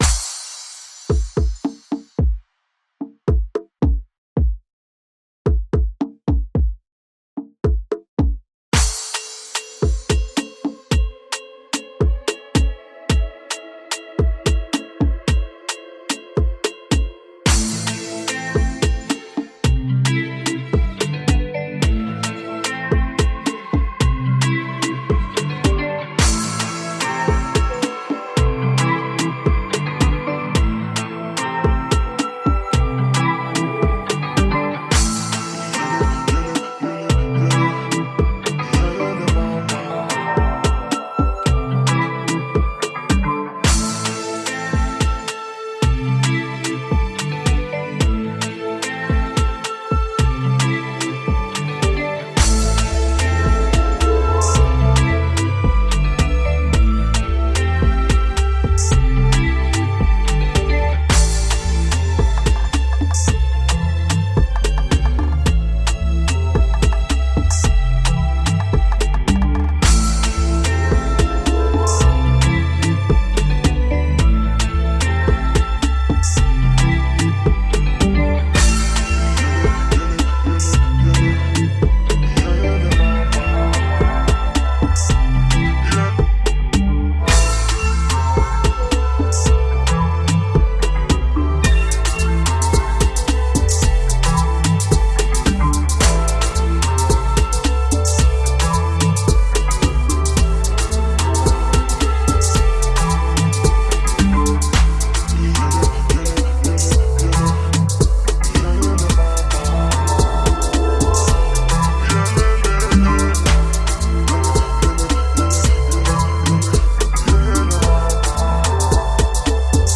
A mix of exotic drum beats, voices, and strings.